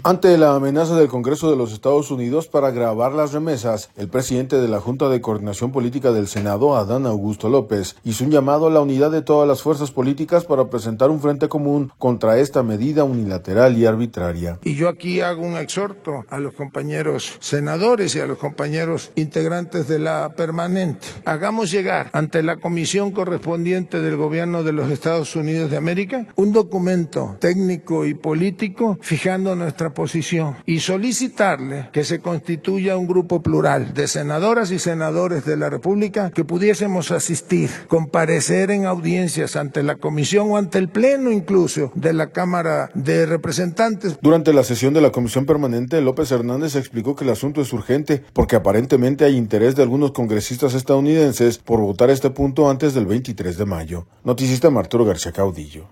Durante la sesión de la Comisión Permanente, López Hernández explicó que el asunto es urgente, porque aparentemente hay interés de algunos congresistas estadounidenses por votar este punto antes del 23 de mayo.